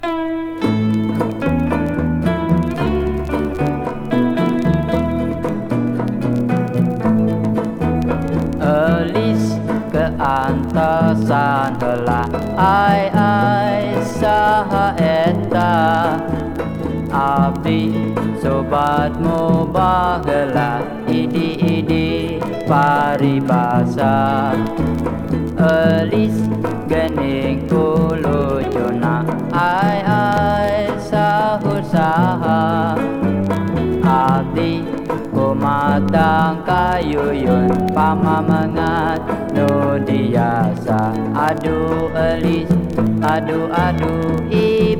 World, Folk, Krontjong　Netherlands　12inchレコード　33rpm　Stereo